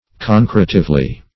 concretively - definition of concretively - synonyms, pronunciation, spelling from Free Dictionary Search Result for " concretively" : The Collaborative International Dictionary of English v.0.48: Concretively \Con*cre"tive*ly\, adv.